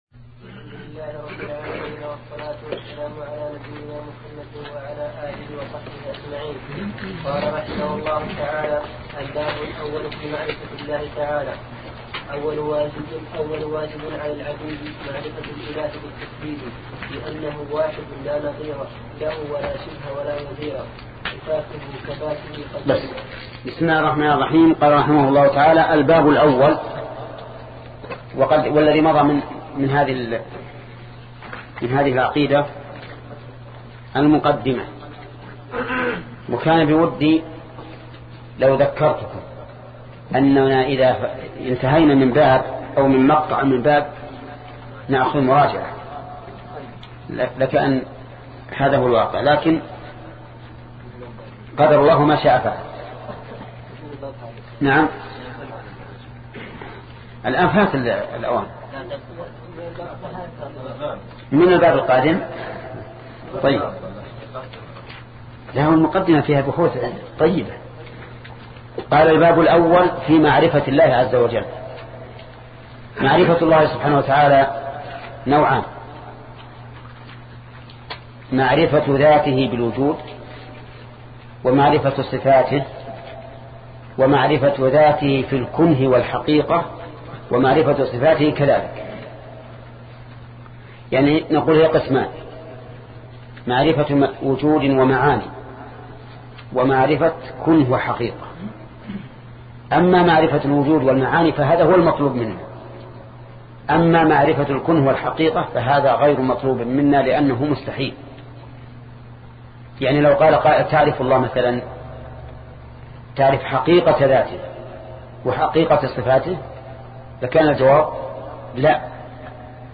سلسلة مجموعة محاضرات شرح العقيدة السفارينية لشيخ محمد بن صالح العثيمين رحمة الله تعالى